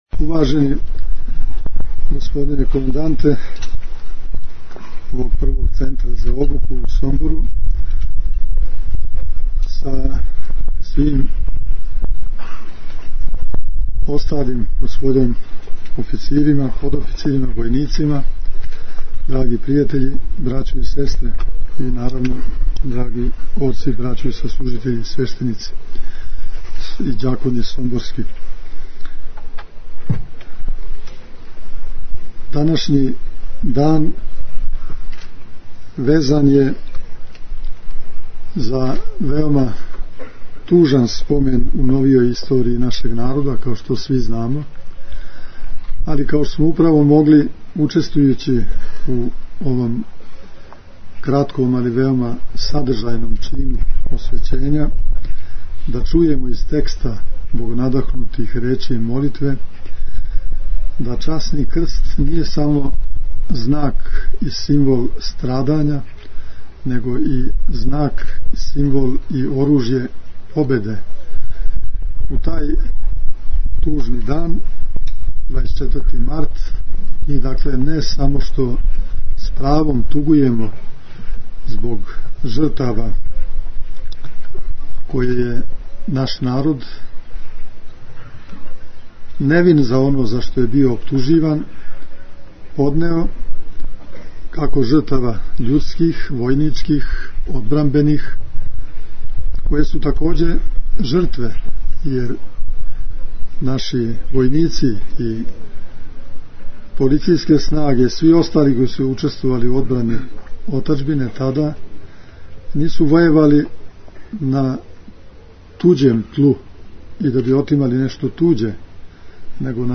Звучни запис беседе Епископа Иринеја